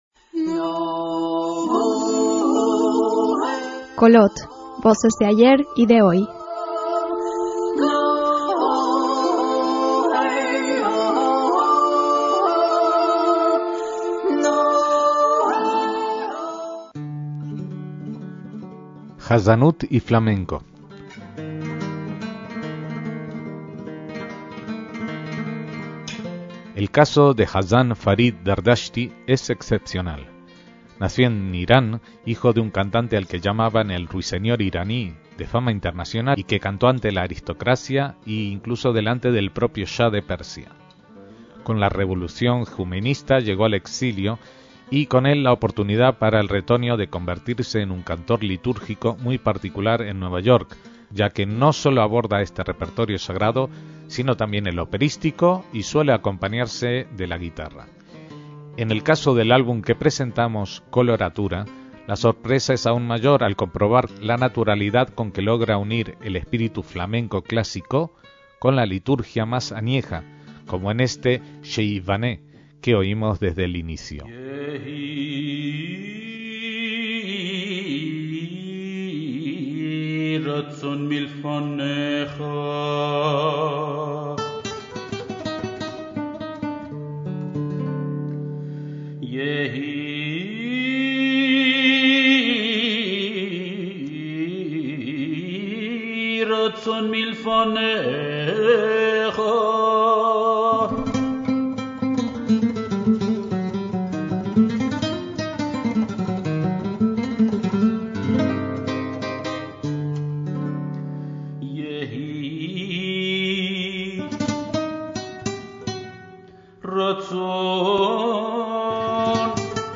flamenco y jazanut